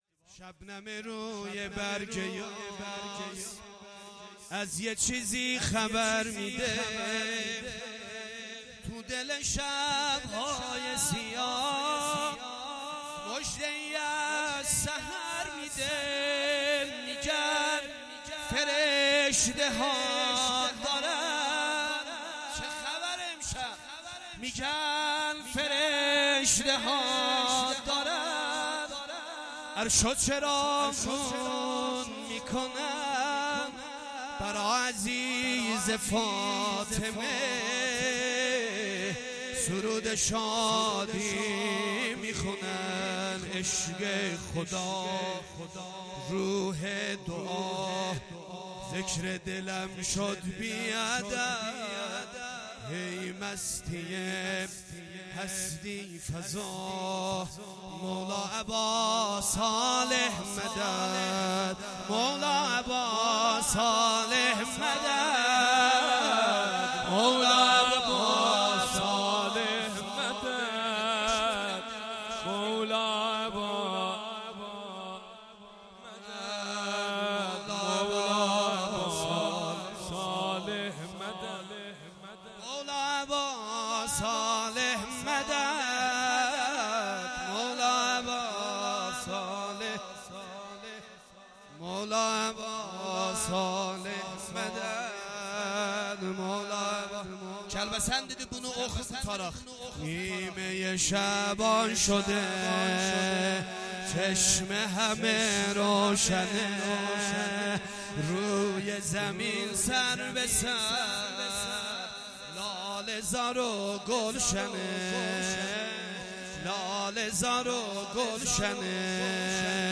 گزارش صوتی جشن نیمه شعبان 1446